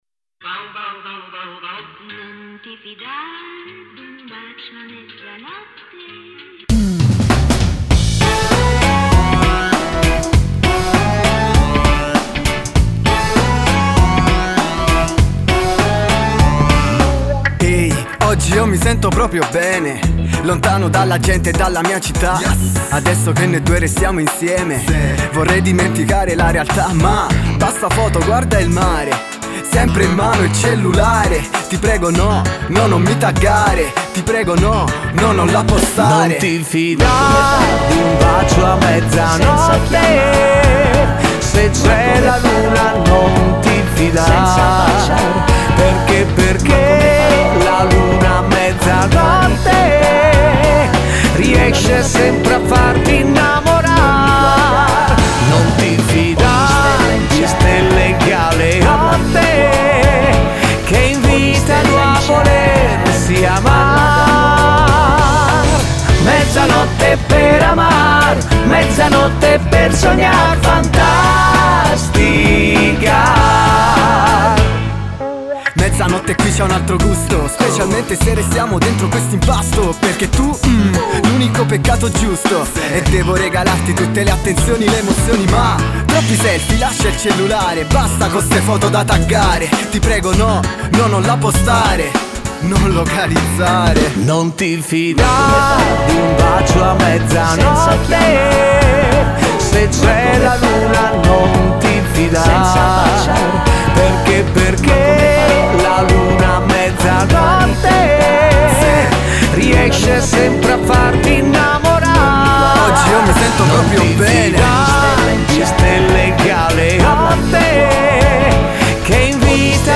in chiave moderna